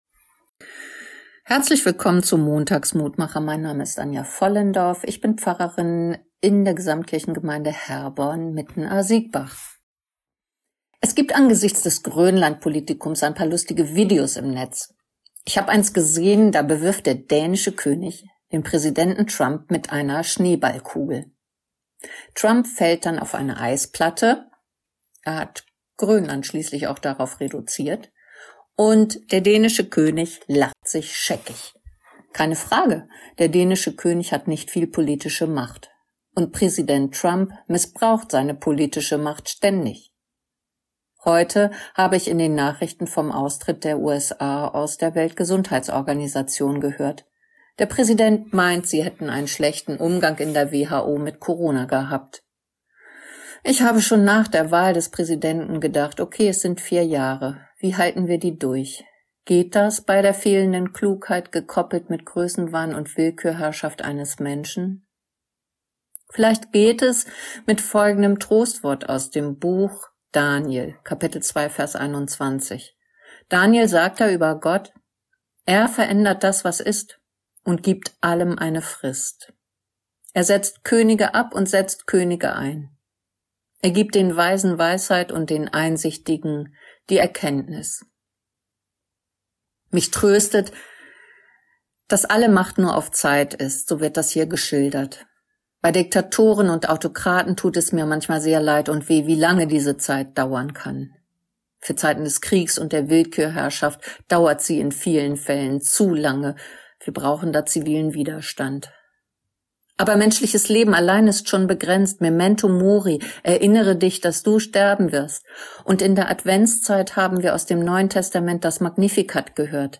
Kurzer täglicher Andachtsimpuls zu Losung oder Lehrtext des Herrnhuter Losungskalender